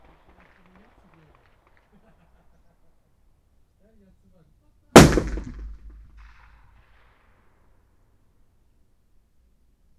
Environmental
Streetsounds
Noisepollution
UrbanSoundsNew / 01_gunshot /shot556_63_ch01_180718_162641_74_.wav